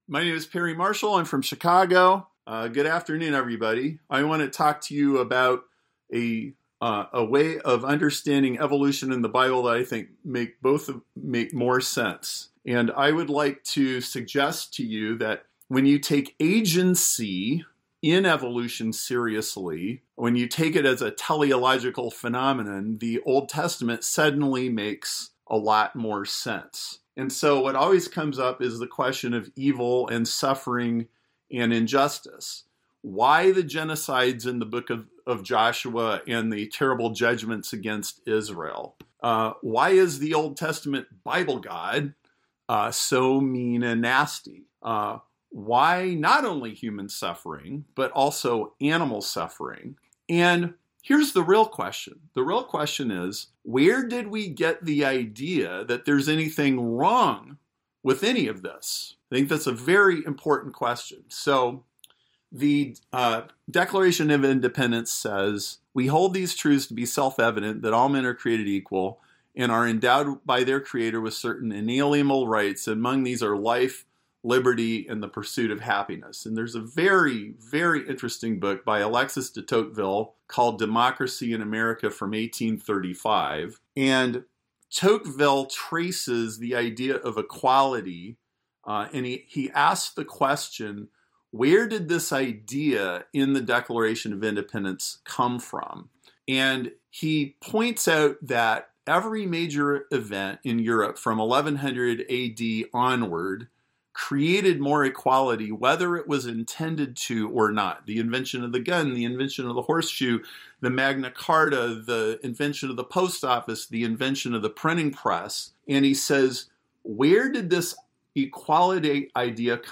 Audio of this presentation Slides in PDF https